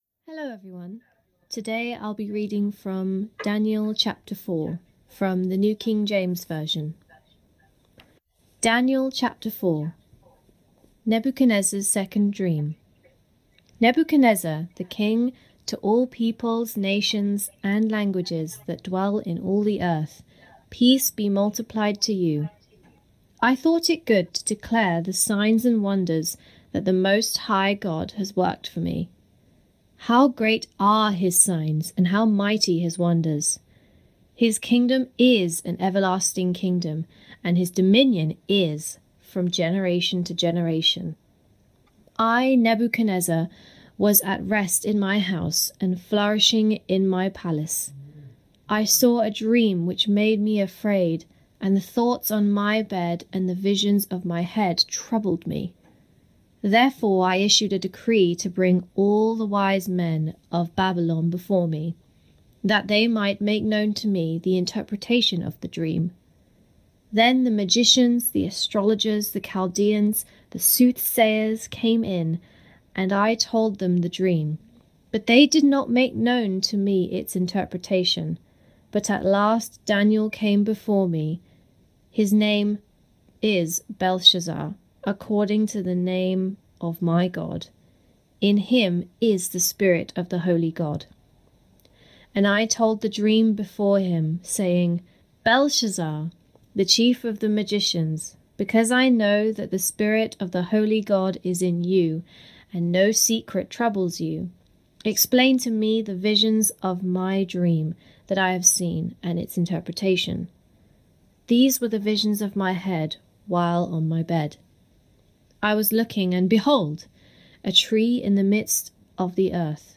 Below is the recording of the sermon for this week.